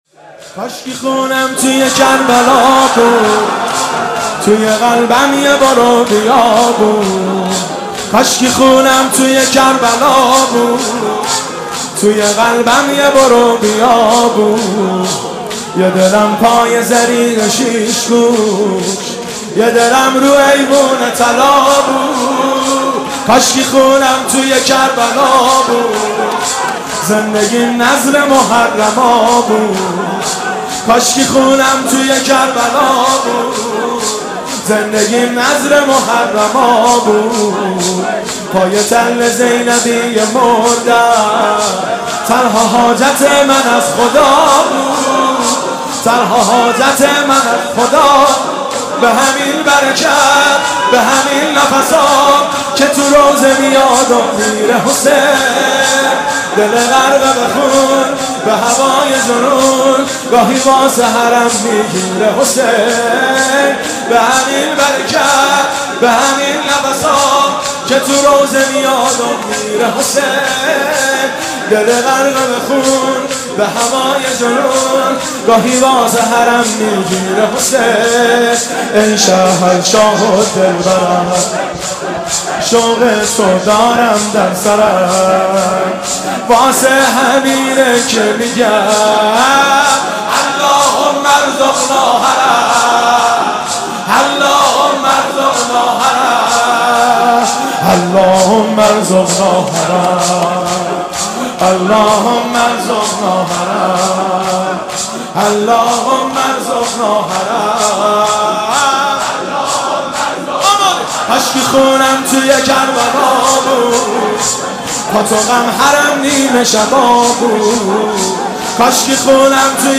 مداحی کاشکی خونم توی کربلا بود(شور)
شب اول محرم 1392
هیئت خادم الرضا (ع) قم